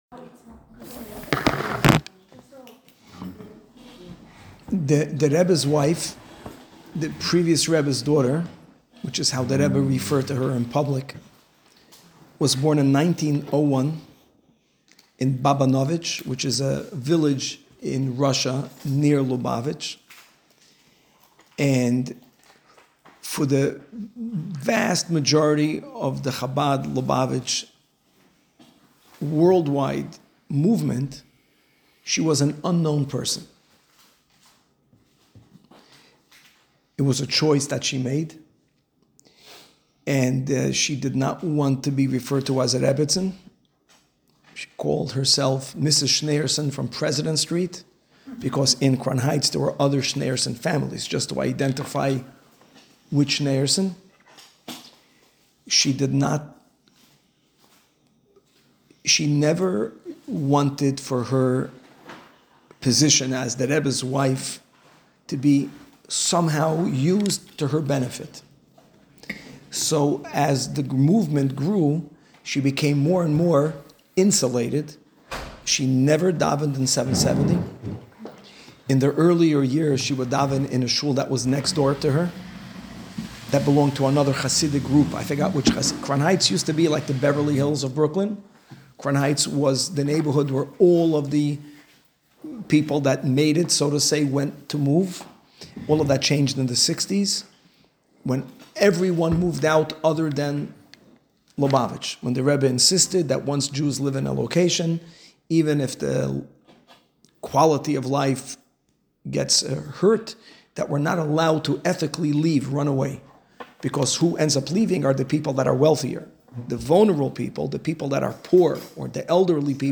Woman's Class